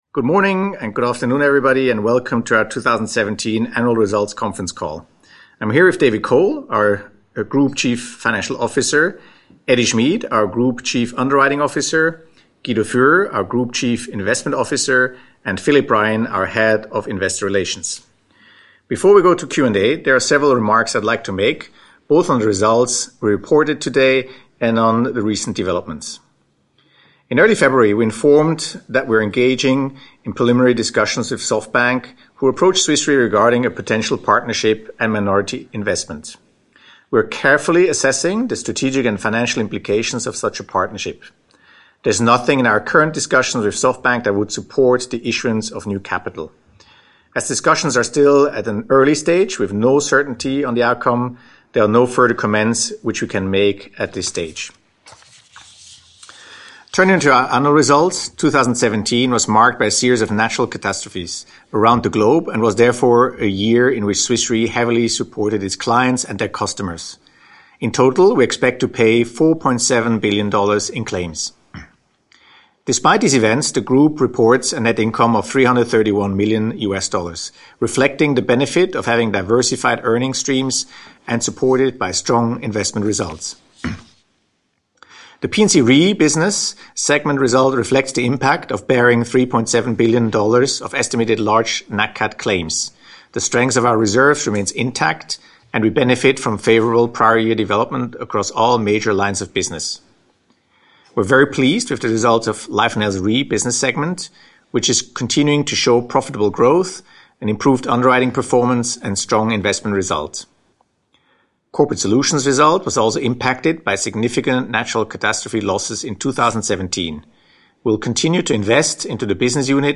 Analysts Conference call recording
2017_fy_qa_audio.mp3